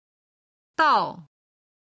到　(dào)　達する、・・まで、